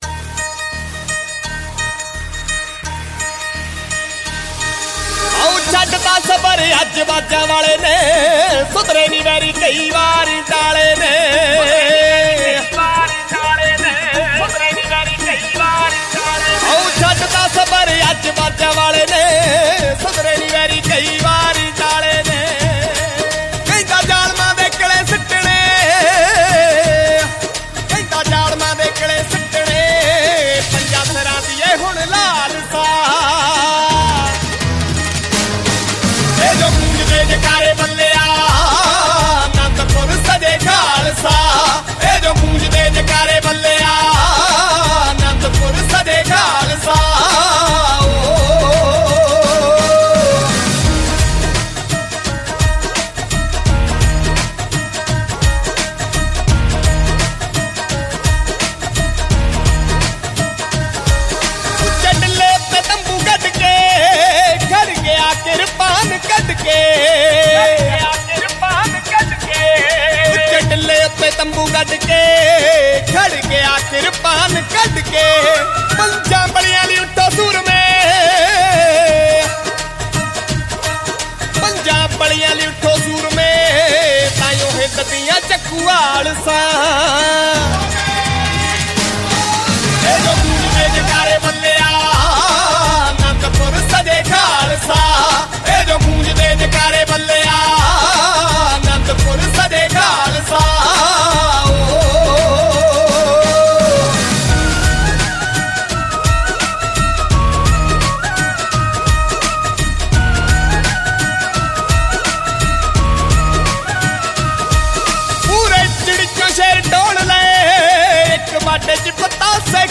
Genre Punjabi Old Song